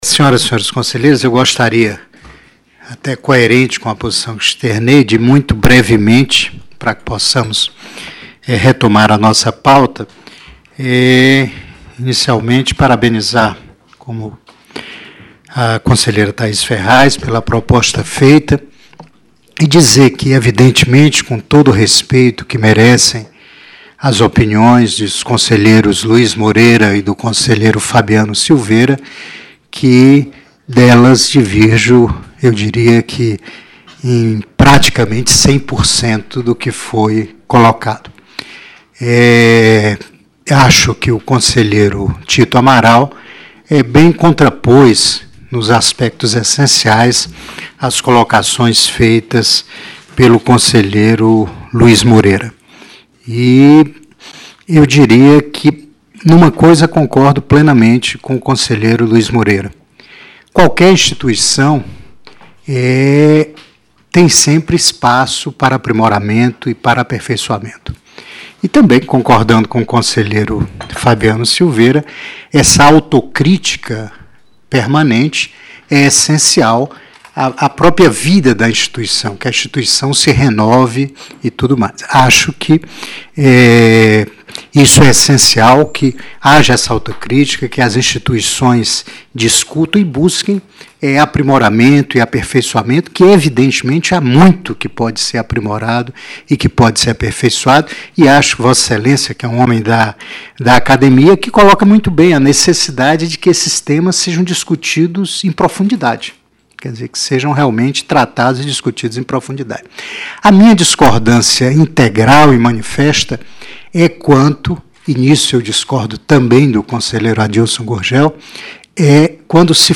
Sessão do Plenário - Conselho Nacional do Ministério Público